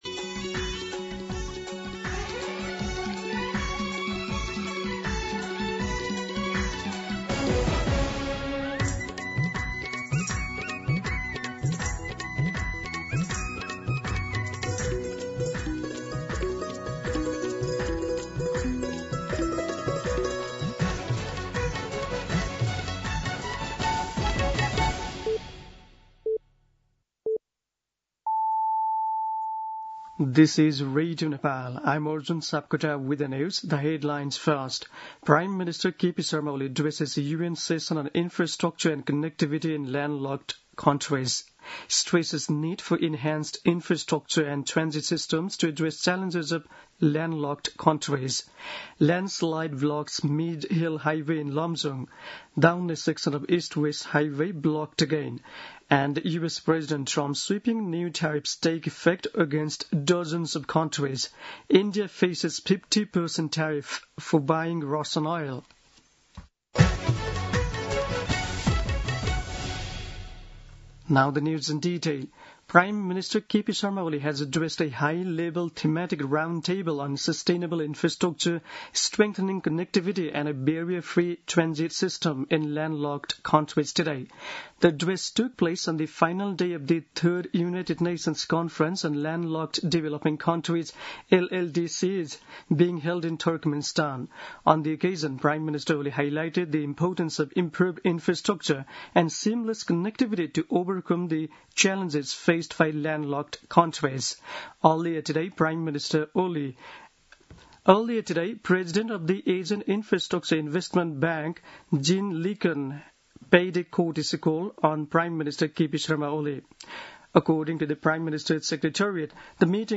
दिउँसो २ बजेको अङ्ग्रेजी समाचार : २२ साउन , २०८२
2-pm-English-News-2.mp3